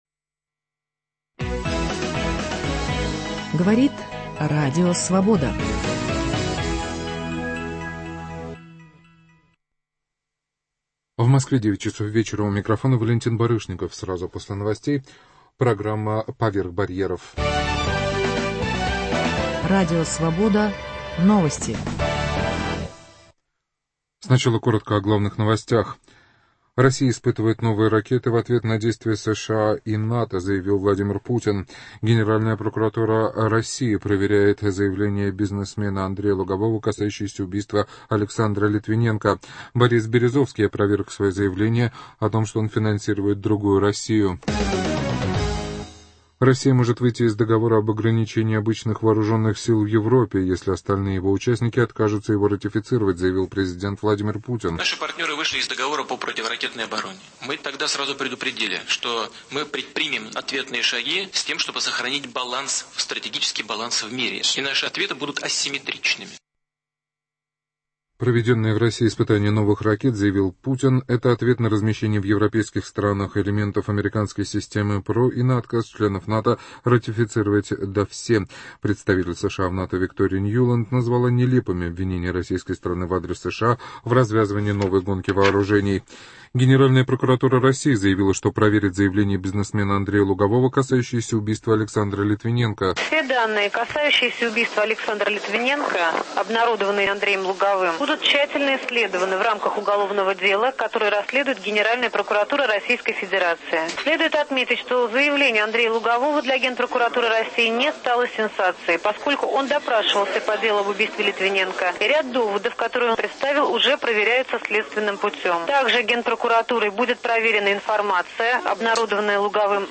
Премьера спектакля Резо Габриадзе в Москве. Интервью с Робертом Стуруа.